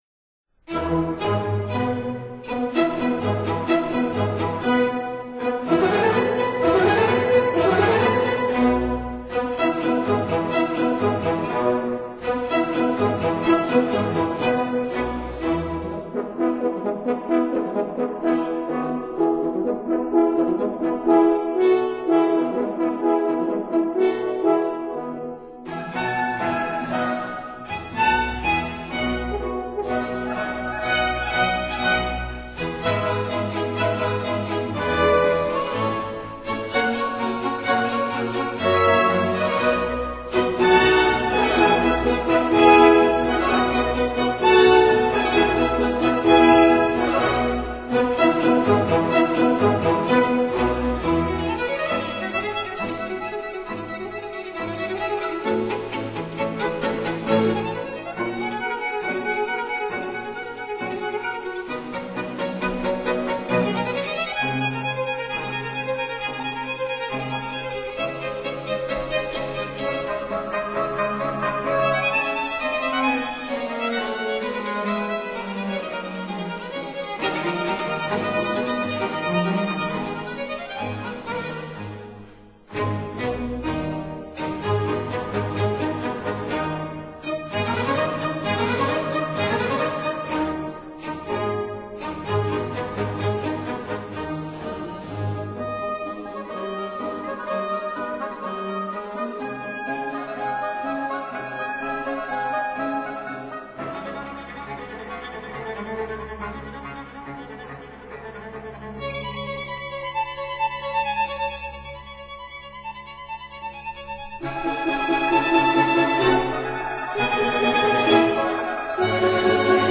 所屬時期/樂派： 巴洛克威尼斯樂派
(Period Instruments)